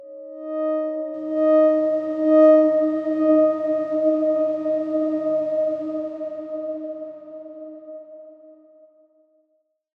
X_Darkswarm-D#4-pp.wav